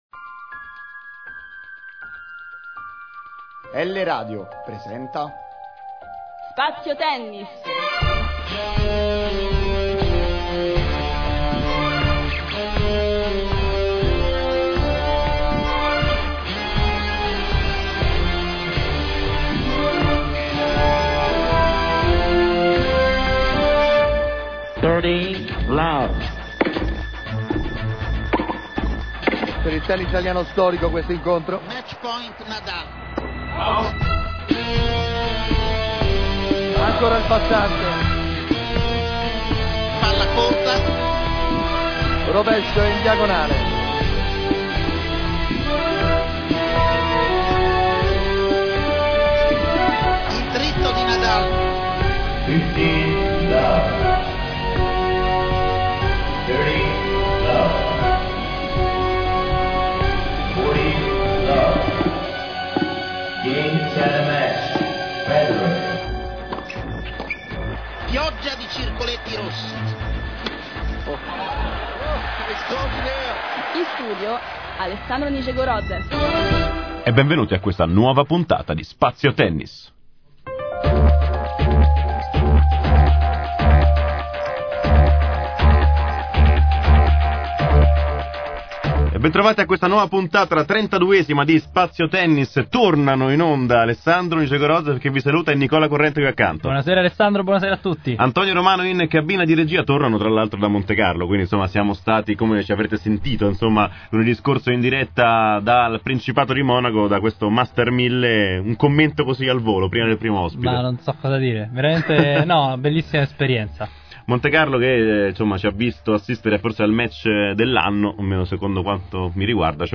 Ecco a voi la replica della trentaduesima puntata di Spazio Tennis, andata in onda lunedì 19 aprile.